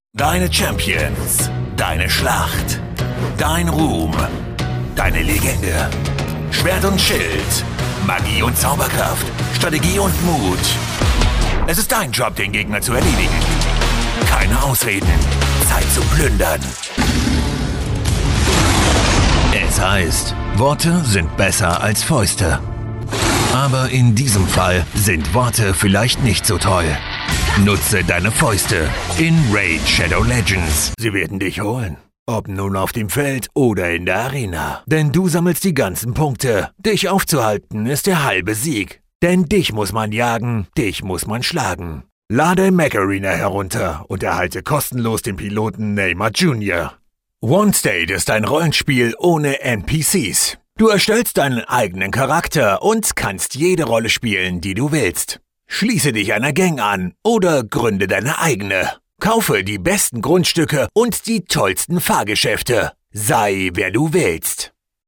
Announcements
I sound dynamic, fresh, powerful, cool and confident and I’m ready to record in my studio 24/7 – also via remote control: Source Connect Now, Session Link Pro, ipDTL, Skype, Microsoft Teams.
microphone: Neumann TLM 49